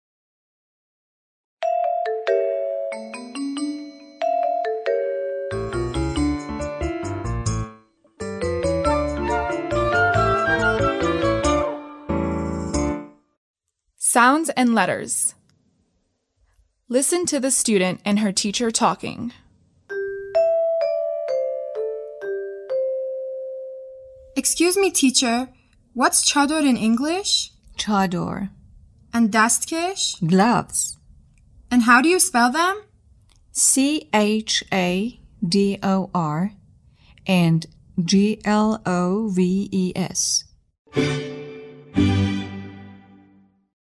به صحبت های بین معلم و دانش آموز گوش کنید: